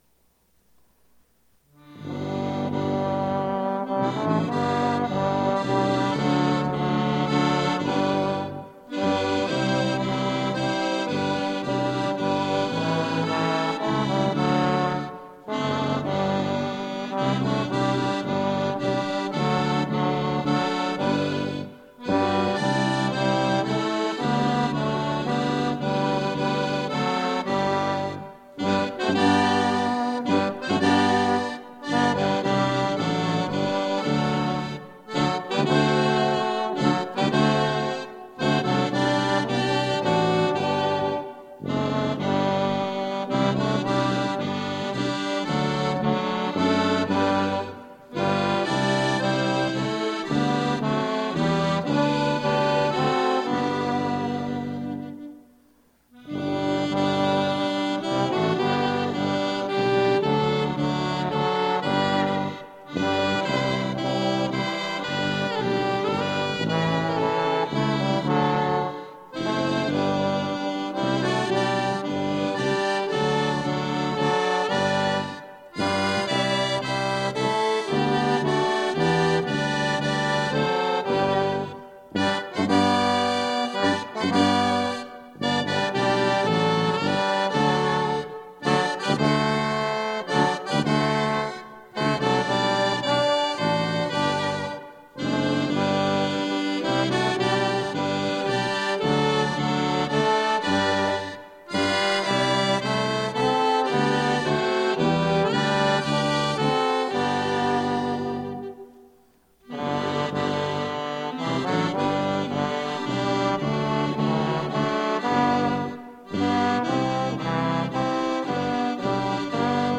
acordeon
sax-alto
trombone
tuba